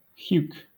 Ääntäminen
Southern England
IPA : /h(j)uːk/